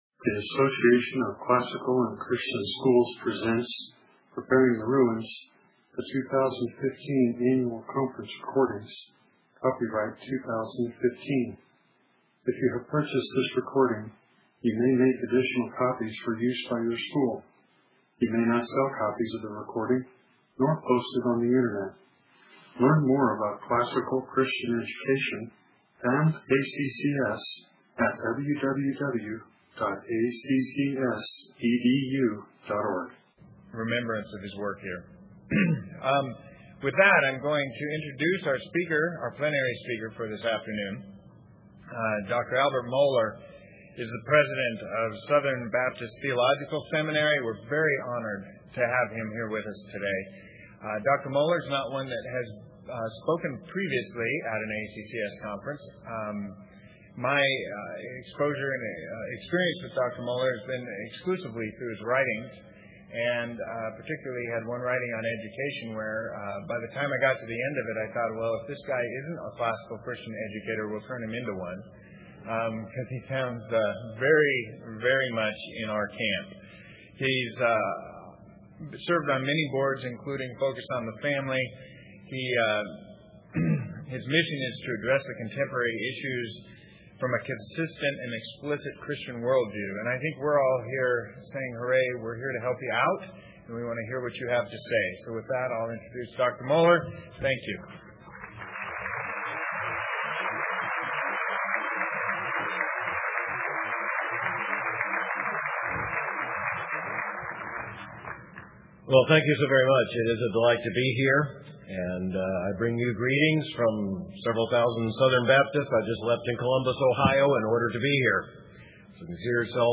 2015 Plenary Talk, 0:59:07, All Grade Levels